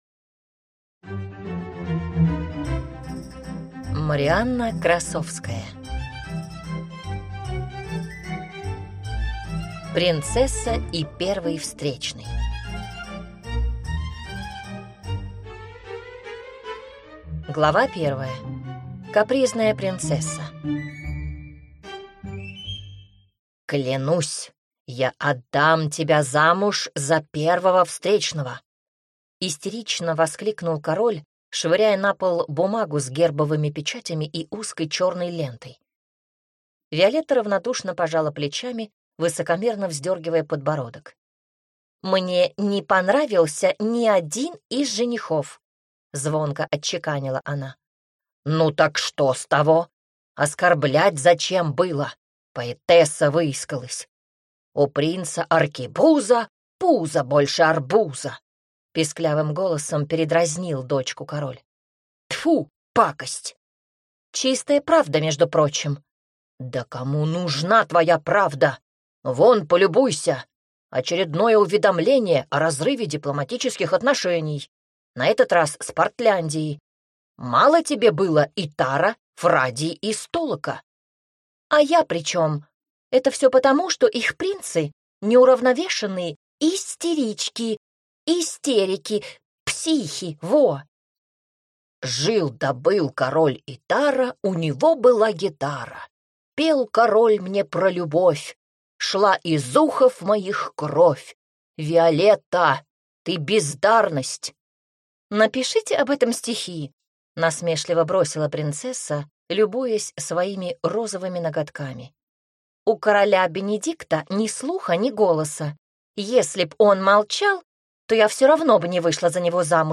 Аудиокнига «Петр I».